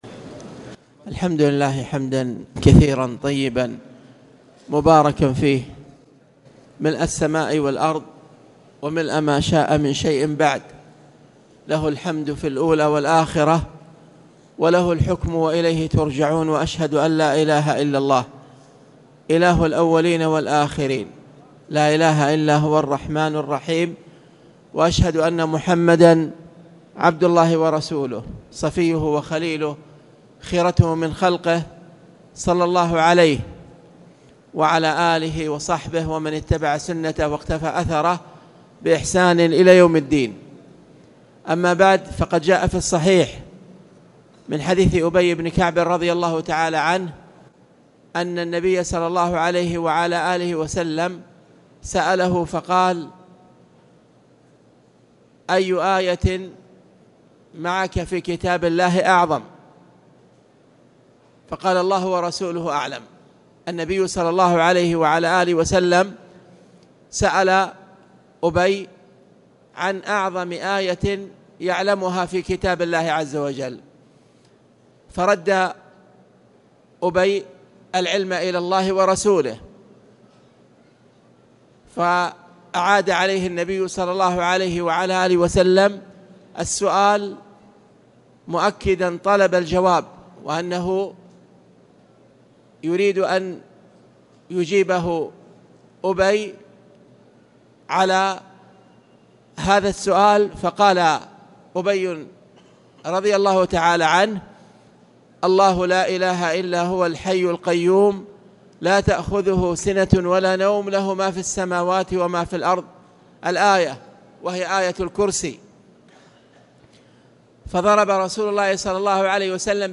تاريخ النشر ٥ جمادى الآخرة ١٤٣٨ هـ المكان: المسجد الحرام الشيخ